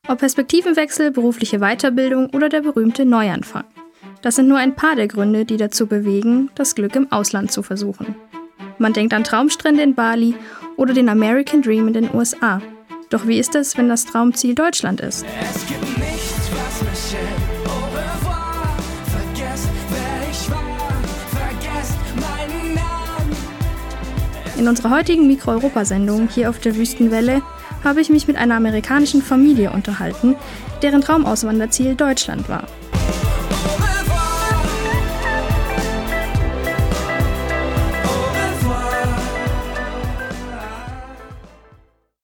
Radio Micro-Europa, der Tübinger Campusfunk: Sendung (349) „Von Amerika nach Deutschland“ am Sonntag, den 7. März 2021 von 12 bis 13 Uhr im Freien Radio Wüste Welle 96,6 – Kabel: 97,45 Mhz, auch als Live-Stream im Internet.